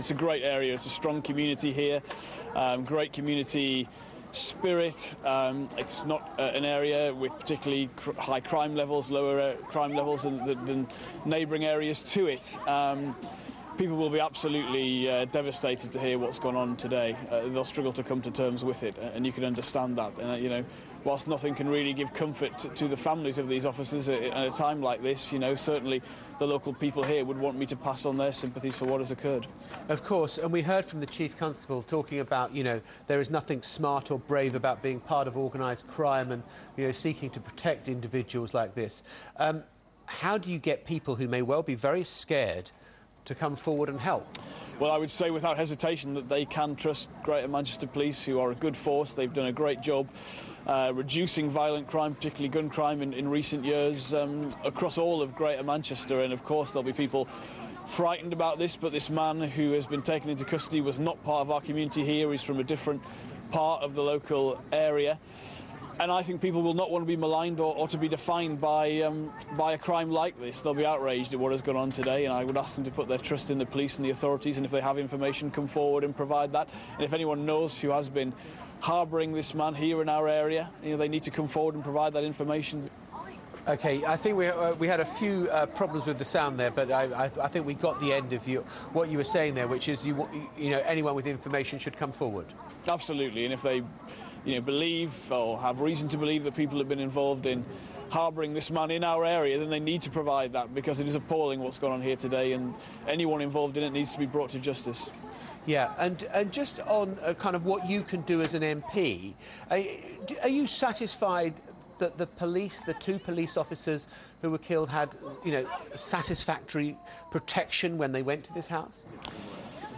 Jonathan Reynolds MP for Stalybridge and Hyde speaks about the community and urges anybody with any information to come forward